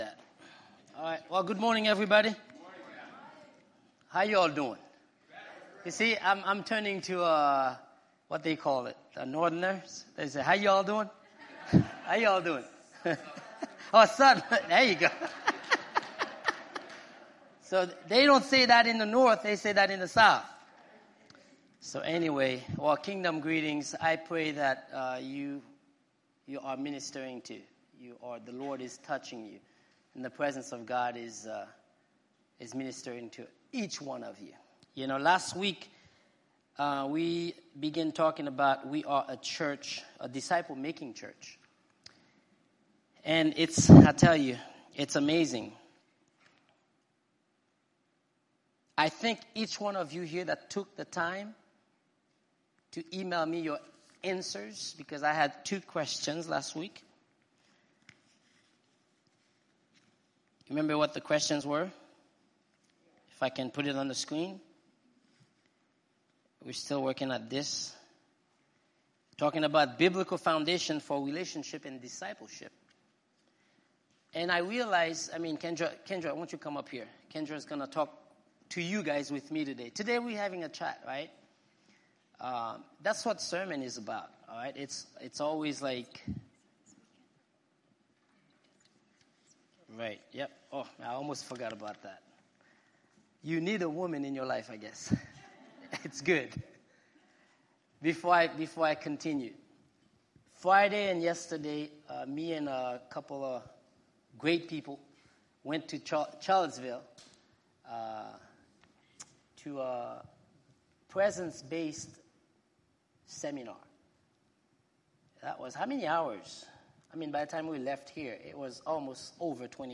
Audio recordings of sermons